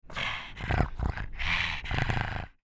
Звуки мопса
Звук дыхания мопса: как дышит собака породы мопс